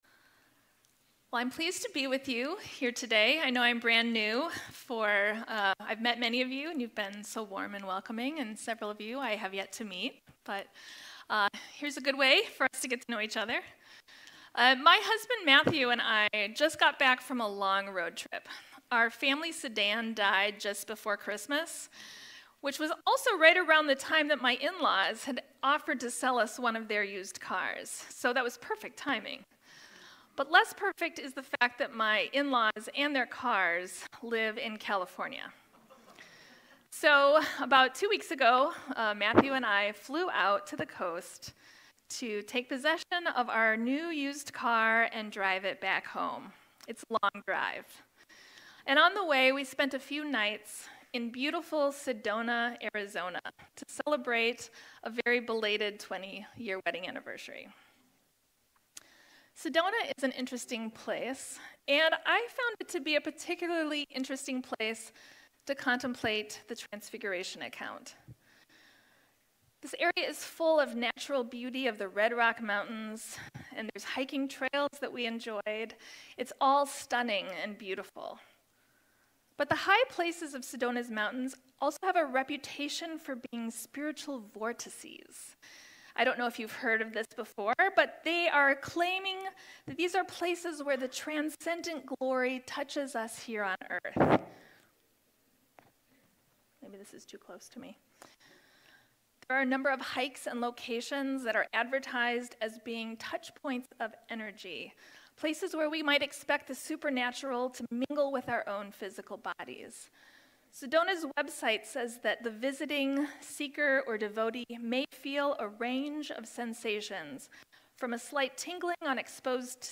Sermons | Faith Covenant Church
Guest Speaker